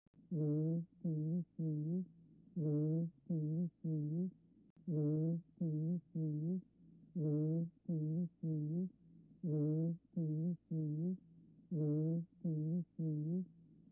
• Hay, además , transmisión neta de la voz hablada (broncofonía) y de la cuchicheada (
pectoriloquia áfona) y, en muchos casos de neumonía, se pueden también auscultar roces pleurales.
Pectoriloquia_anormal.mp3